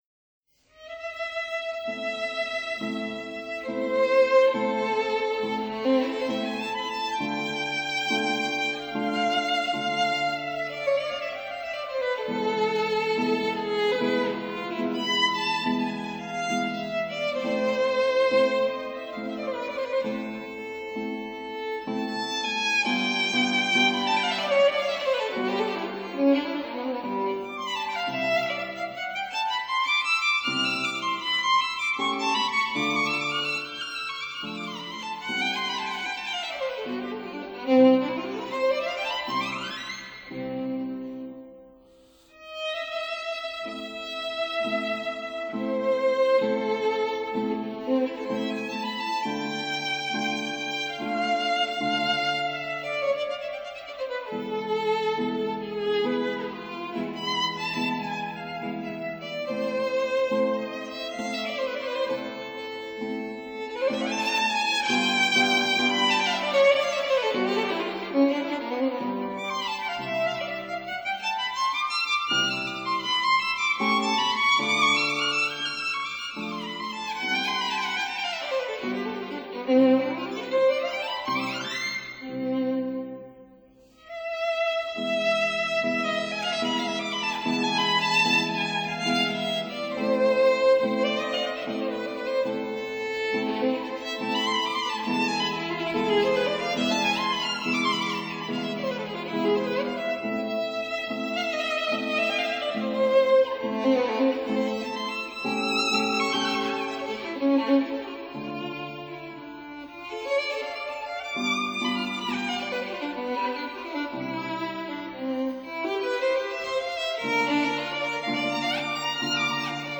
(Period Instruments)